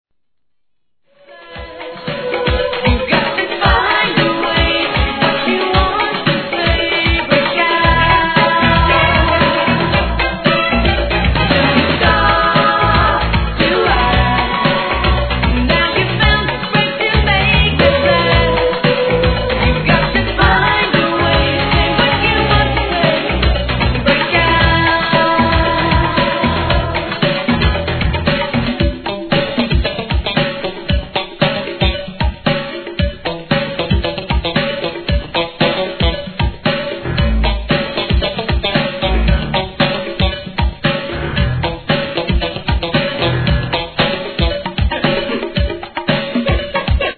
12inch
HIP HOP/R&B
'80sの人気POPSのデビューアルバムからの人気シングル!!